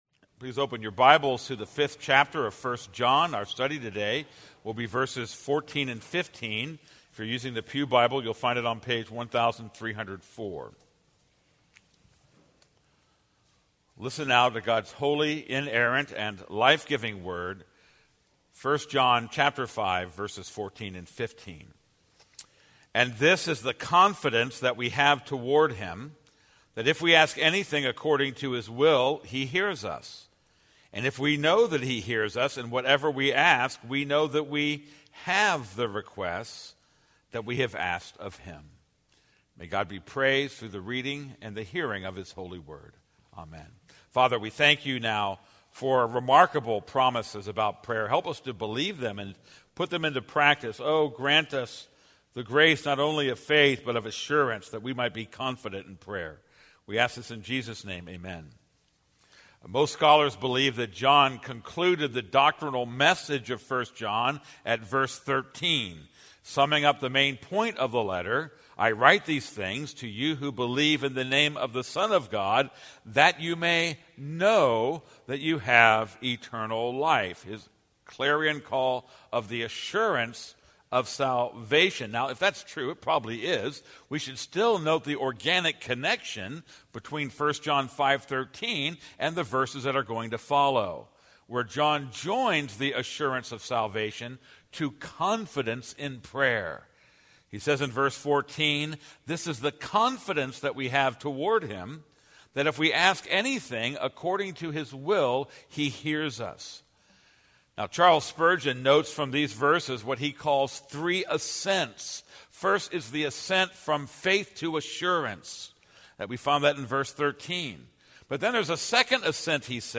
This is a sermon on 1 John 5:14-15.